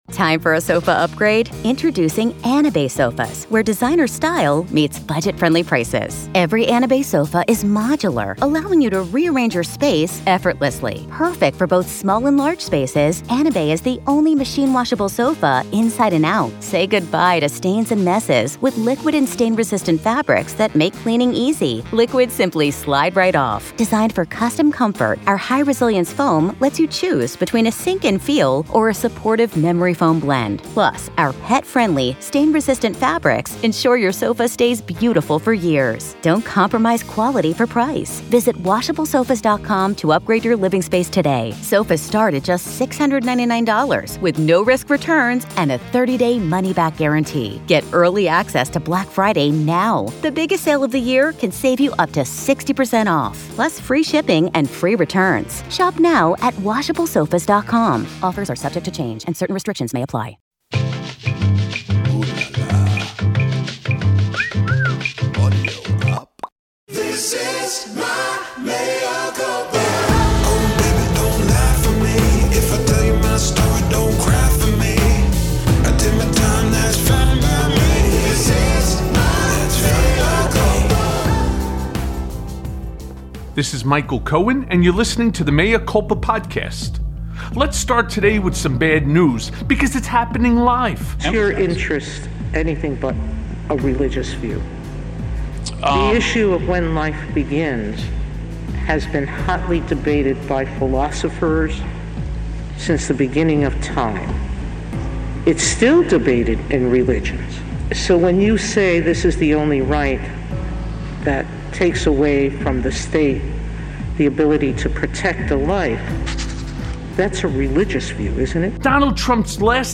Breaking!!! Super Spreader Trump Might Have Killed Dozens + A Conversation with Congressman Steve Cohen